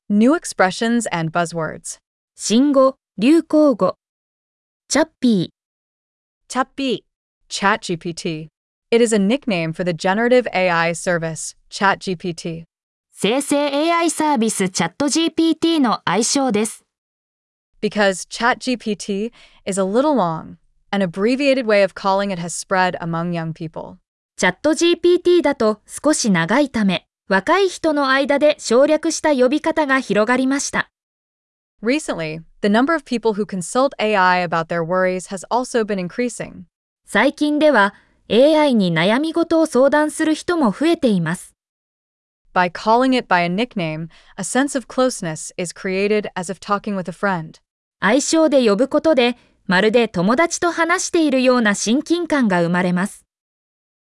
🗣 pronounced: Chappi-